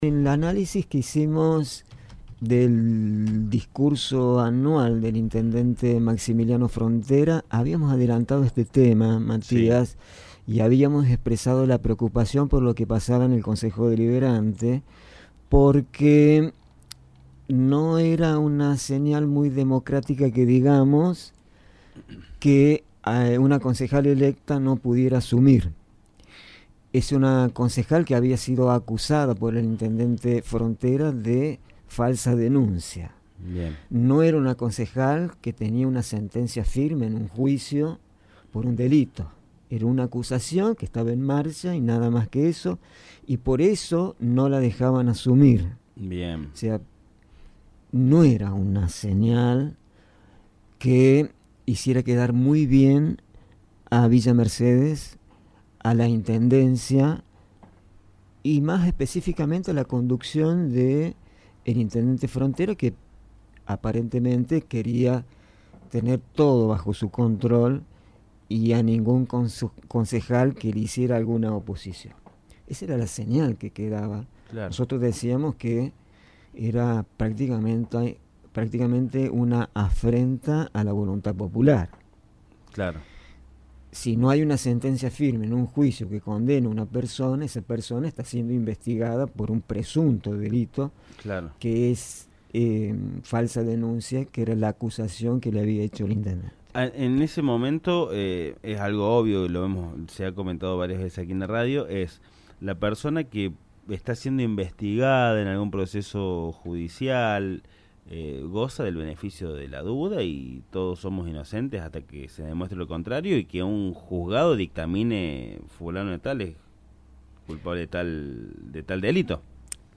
En la columna de opinión “Análisis con Memoria”, nos referimos a la tardía asunción de Andrea Quiroga en el Concejo Deliberante de Villa Mercedes, quien finalmente juró en su banca tras casi tres meses de conflicto político e institucional.